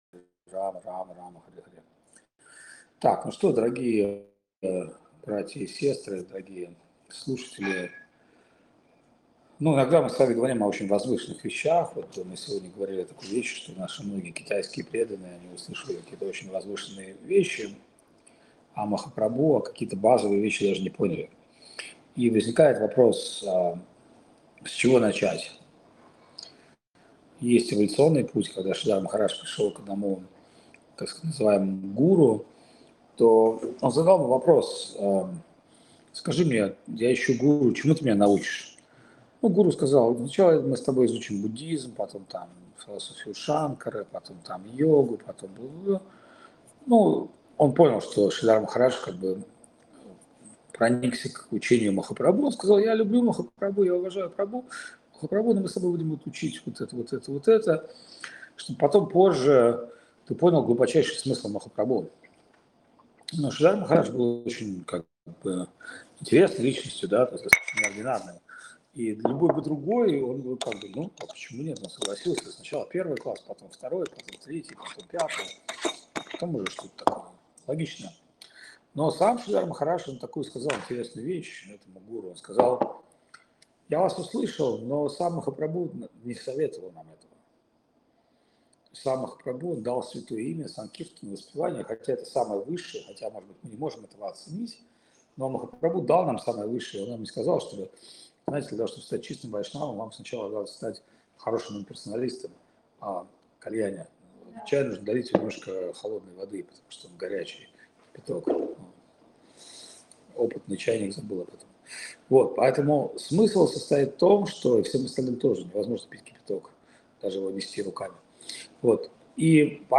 Лекции полностью